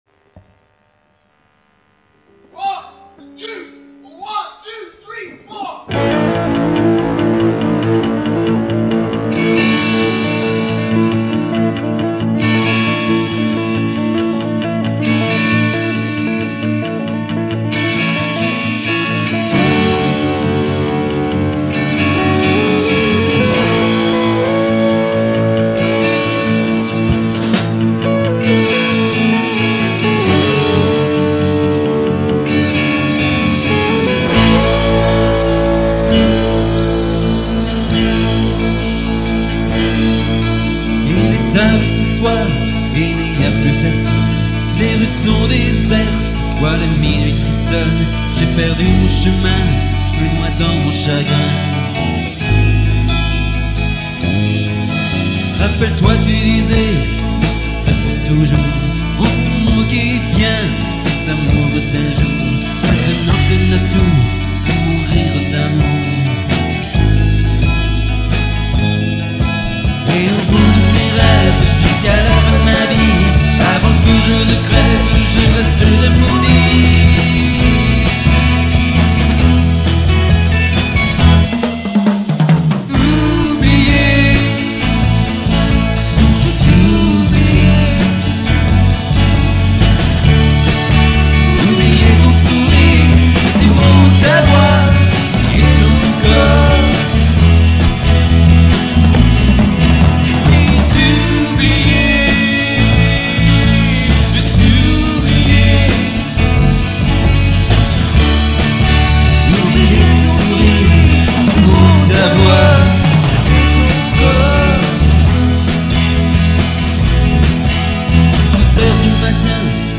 Guitare solo
Basse
Batterie
Chant et guitare rythmique